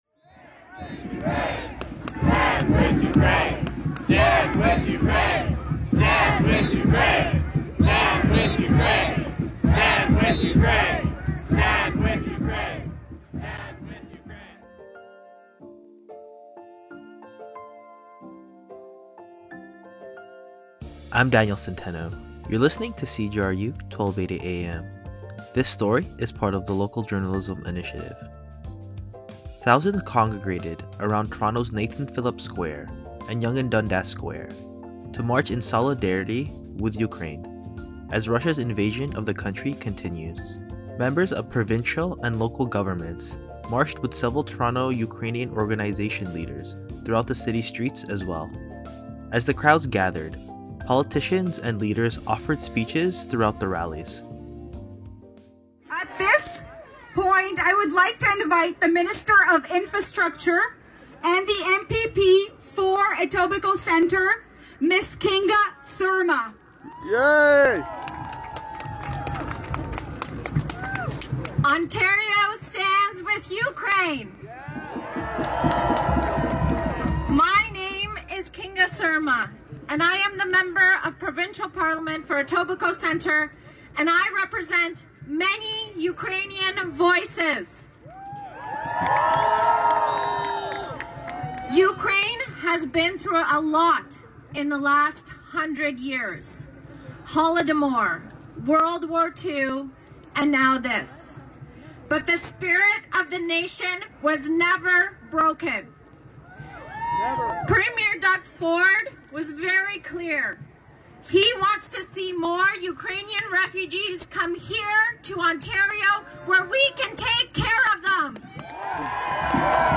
As the crowds gathered, politicians and leaders offered speeches throughout the rally, calling on Russian President Vladimir Putin to withdraw his armed forces from Ukraine, urging western nations to offer financial and military aid to the defence of Ukraine and to establish a no-fly zone in areas where cilivians are journeying towards the Polish and Hungarian borders.